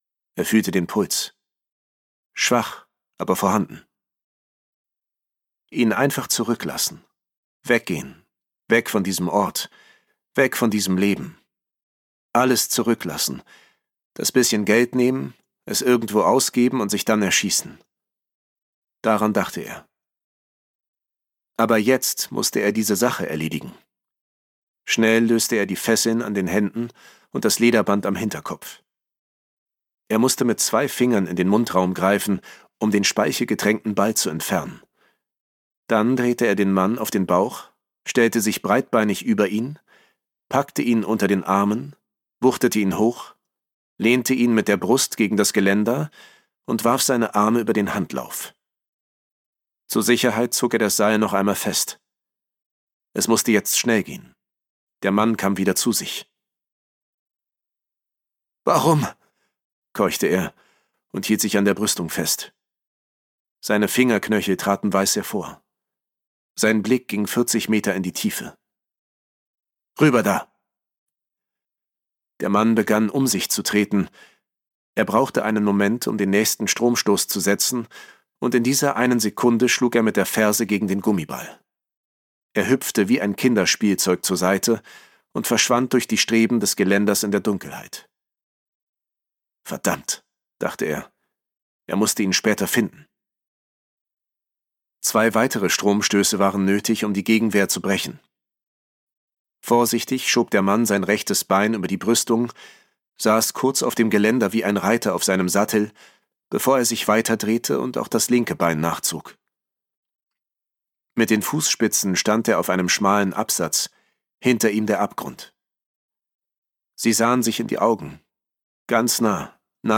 Spannend und dicht erzählt.